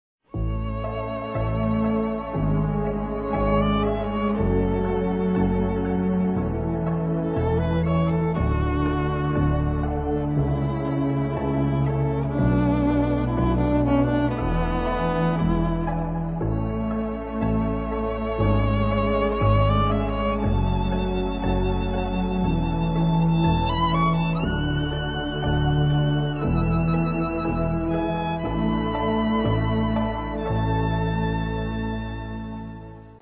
Générique de la bande originale de la série télévisée